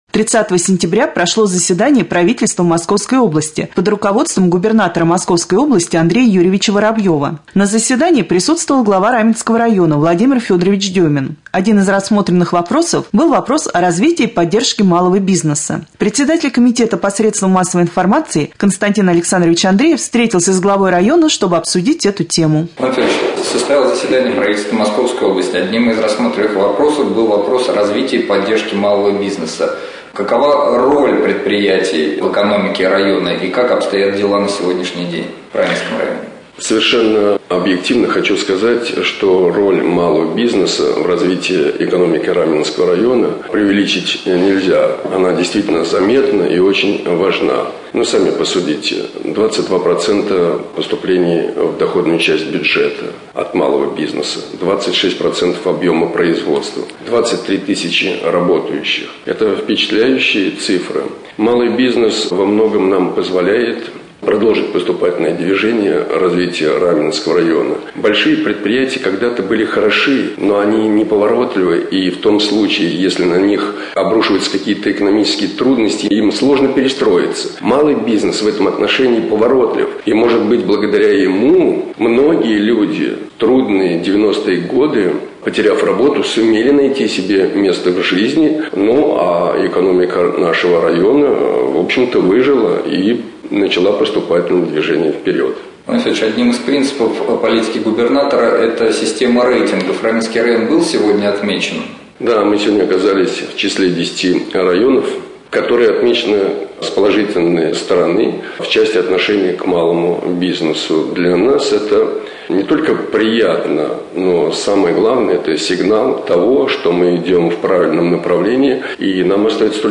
3.Рубрика «Специальный репортаж». Председатель комитета по СМИ К.А. Андреев побеседовал с главой района В.Ф. Деминым по вопросу поддержки и развитии малого бизнеса.